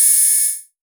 Vermona Open Hat 02.wav